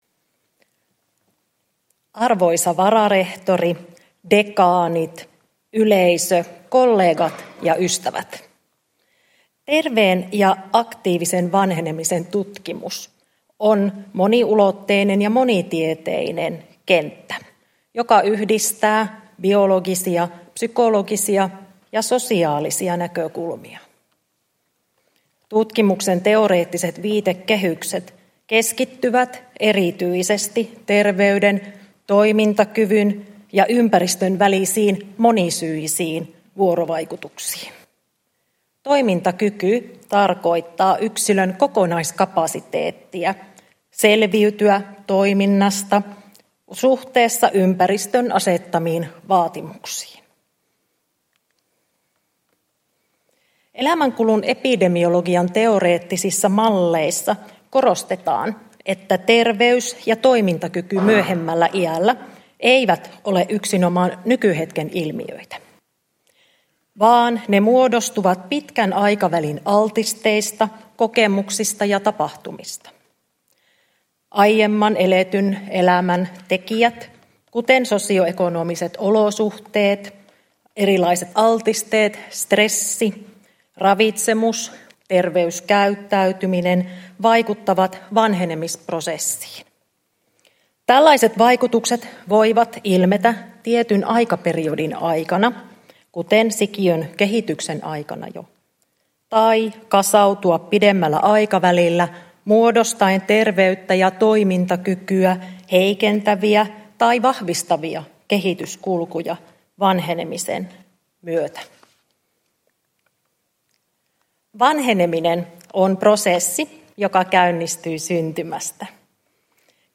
Uusien professoreiden juhlaluennot 2024